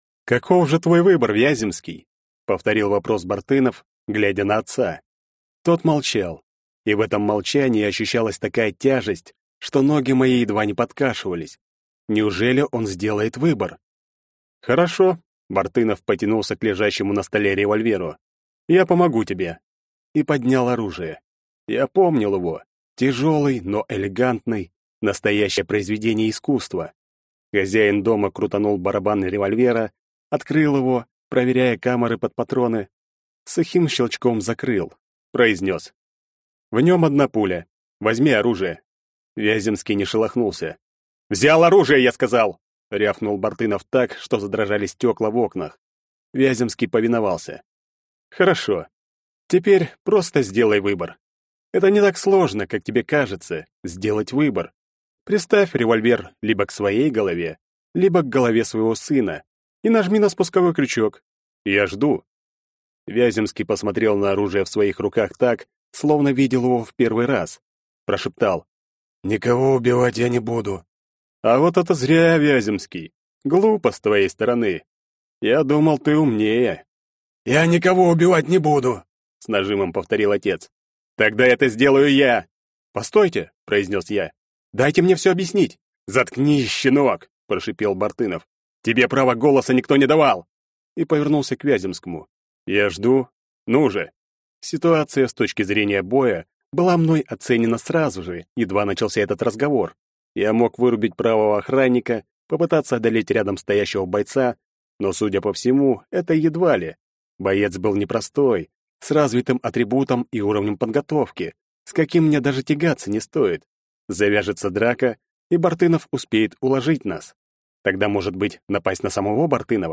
Аудиокнига Фаворит Смерти. Книга 2 | Библиотека аудиокниг